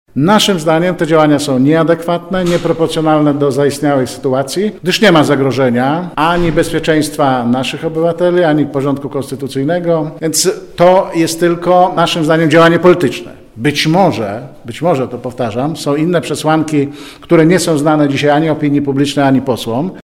• mówi poseł Jacek Czerniak.